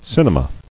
[cin·e·ma]